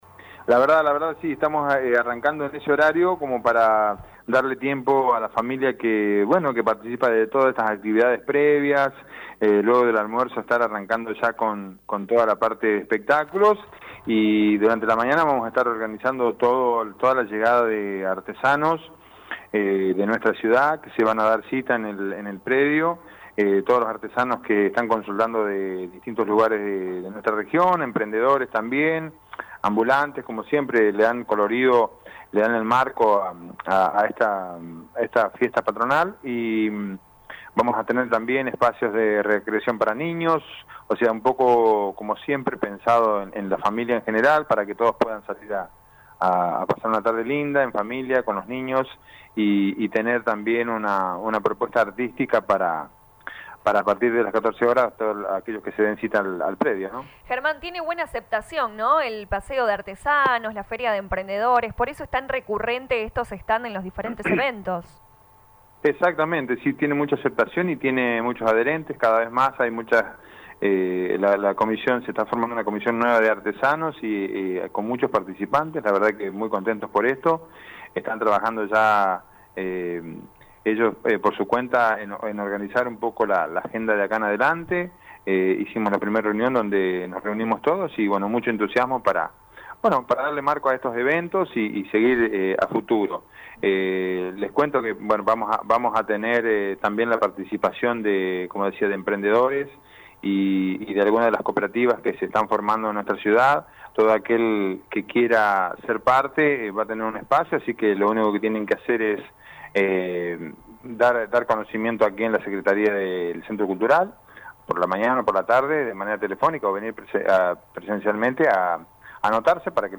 En diálogo con LA RADIO 102.9 FM el secretario de Cultura Germán Argañaráz informó que el viernes desde las 14:00 horas en el predio del Ferrocarril comenzarán las actividades con paseo de artesanos, feria de emprendedores, servicio de buffet y cantina y espectáculos musicales en vivo.